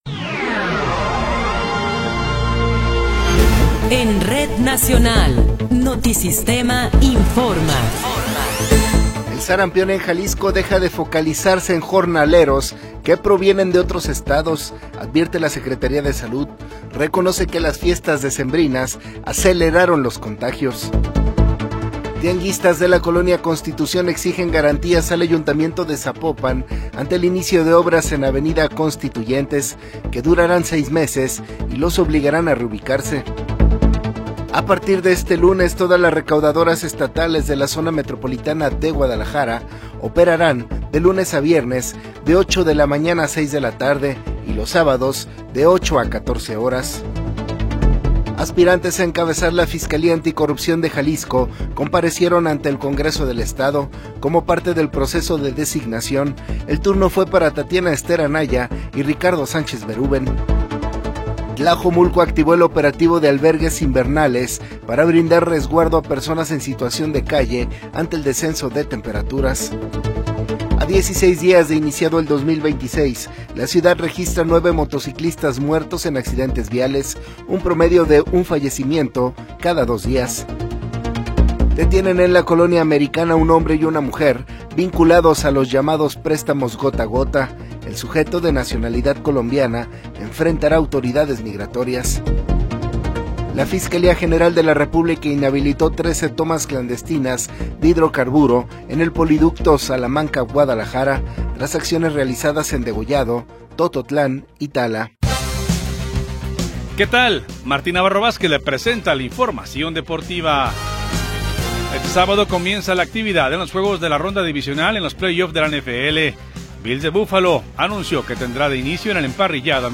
Noticiero 21 hrs. – 16 de Enero de 2026